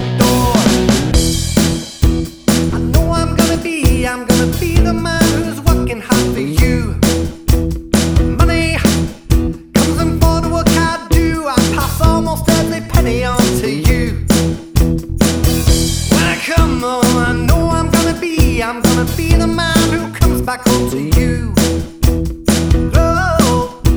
With Harmony Pop (1980s) 3:34 Buy £1.50